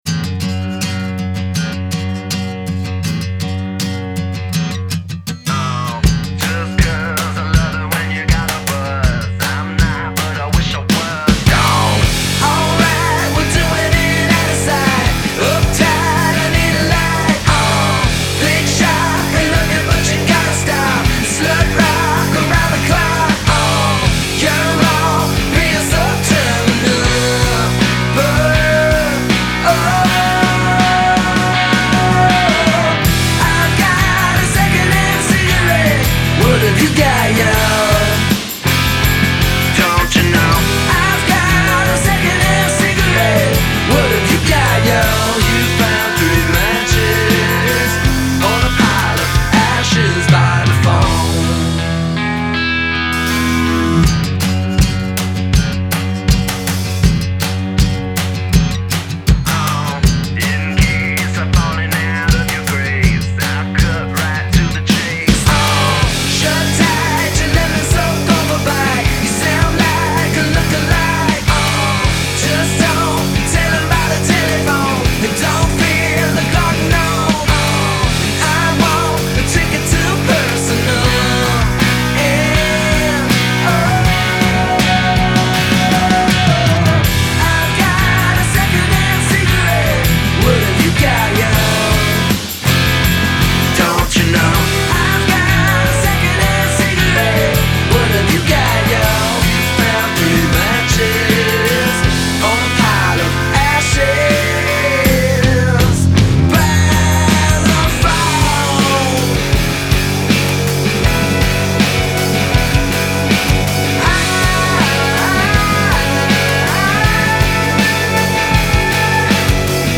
Genre: Alternative.